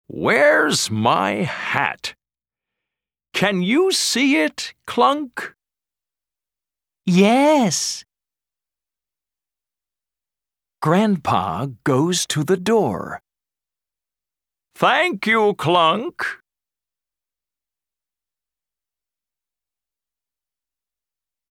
Track 4 Where's My Hat US English.mp3